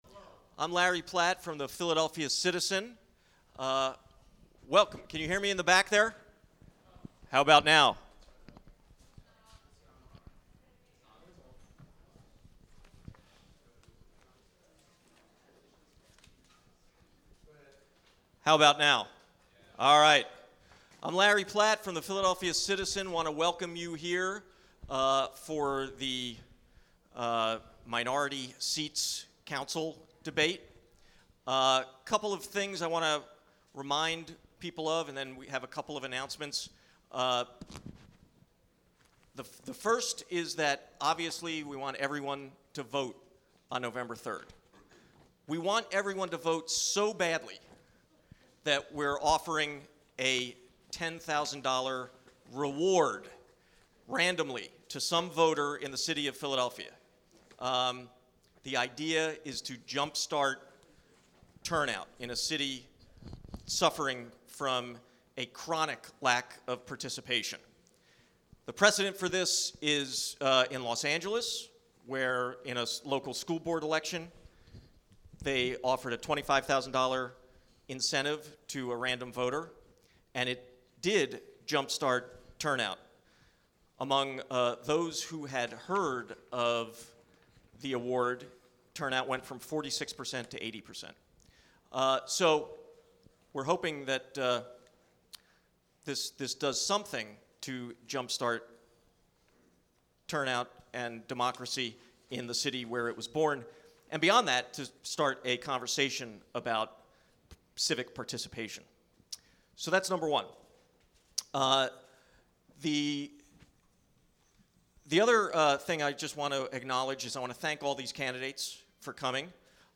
The Philadelphia Citizen's No-Holds-Barred City Council Minority Seat Debate had some fireworks—and a lot of substance.
You may have heard about the excitement at last week’s Citizen debate, featuring five of the minority party at-large City Council candidates: Republican incumbent Dennis O’Brien refused to give up the mic, was heckled by the audience, wouldn’t respond to questions from other candidates, and then walked off the stage—twice.